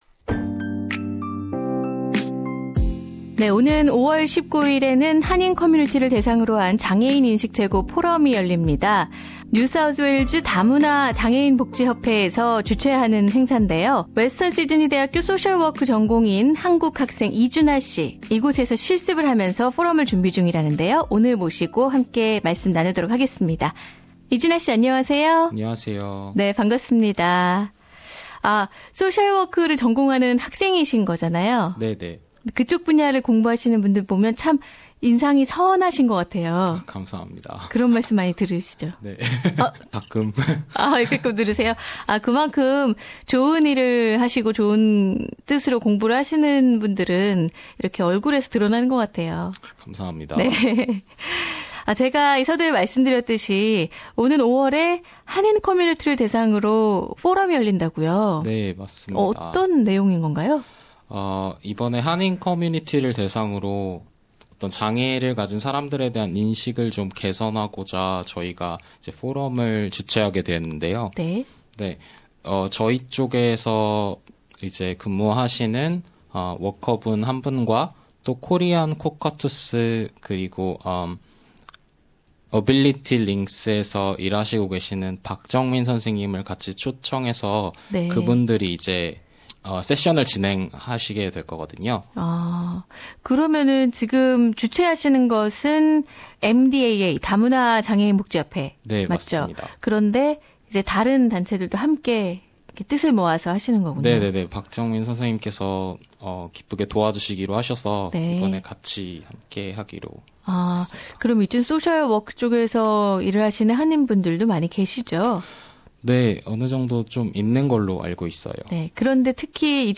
[Interview] MDAAKorean Community Disability Awareness Forum